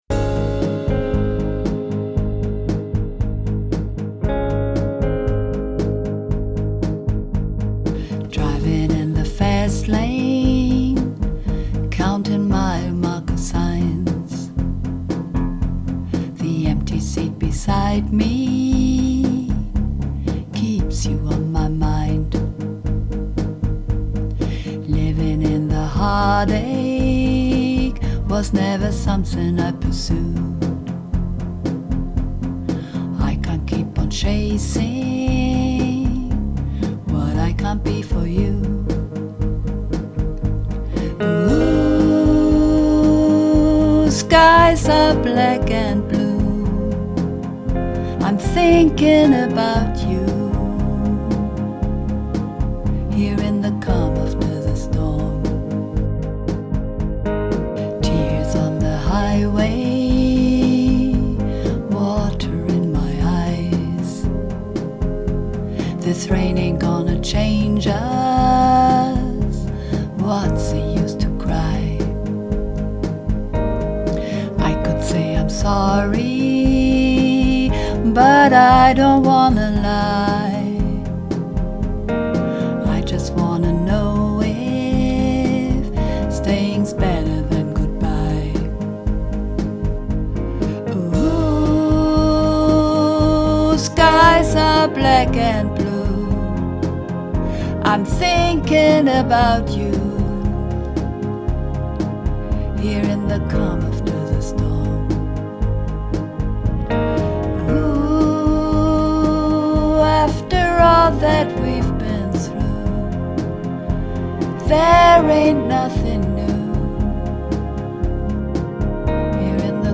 (Tief)